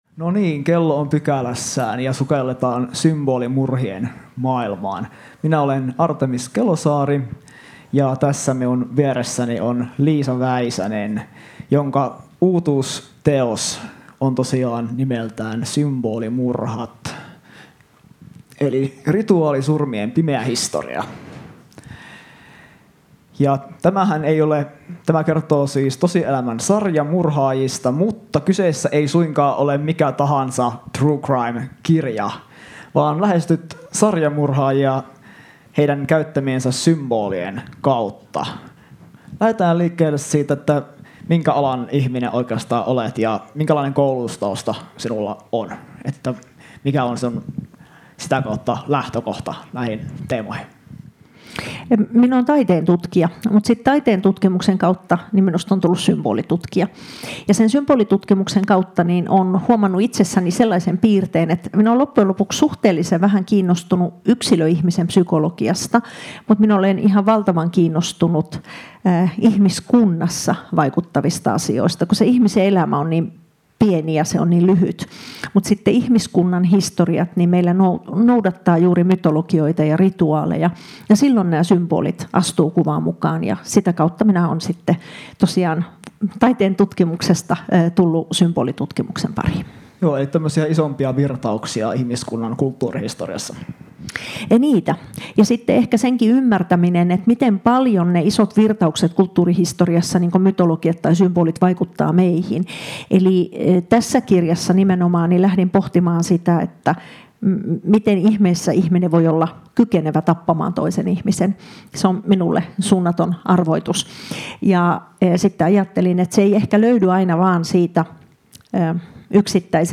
Ohjelma on taltioitu Tampereen Kirjafestareilla 2024. https